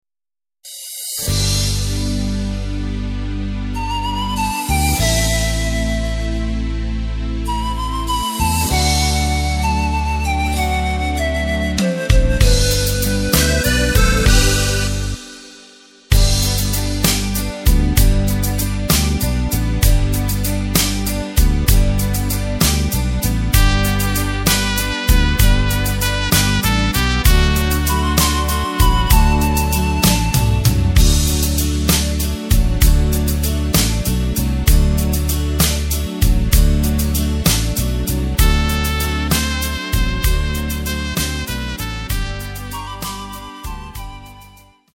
Takt:          6/8
Tempo:         97.00
Tonart:            G
Walzer Instrumental TROMPETE aus dem Jahr 1994!
Playback mp3 Demo